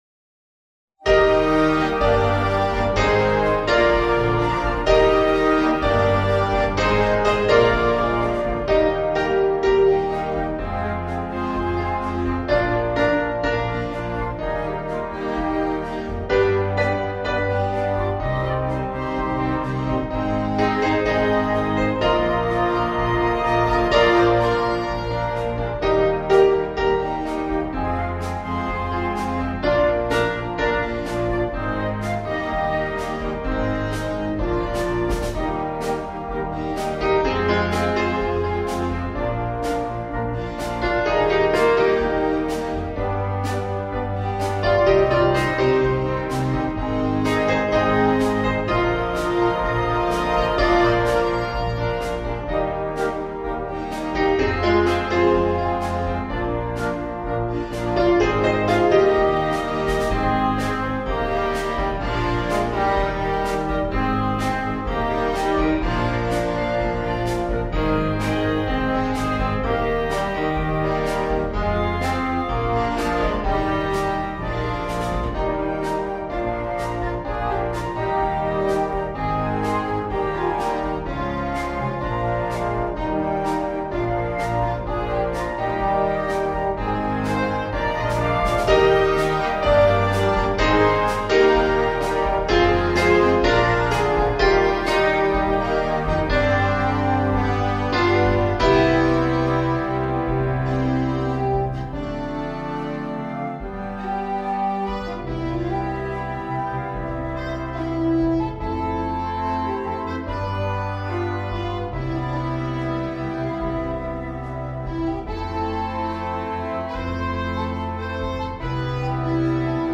2. コンサートバンド
フルバンド
ソロ楽器なし
軽音楽